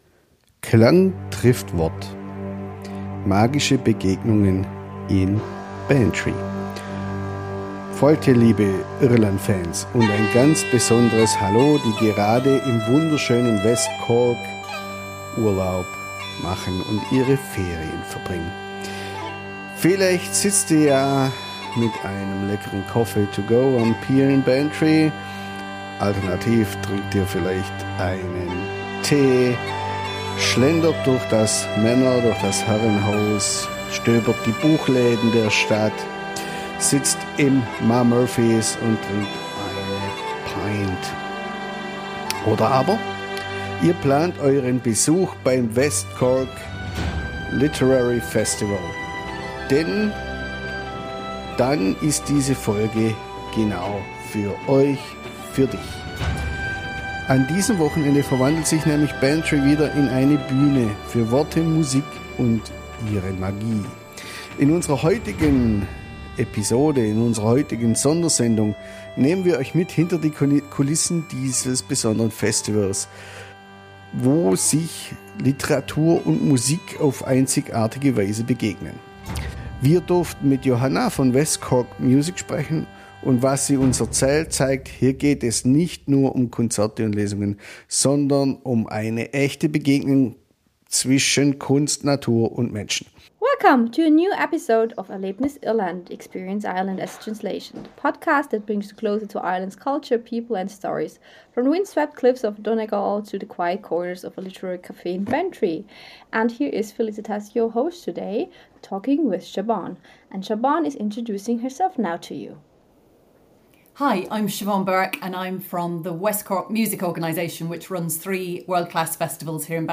Ein Gespräch über Klang, Poesie und die Magie der Begegnung in einem der schönsten Winkel Irlands Mehr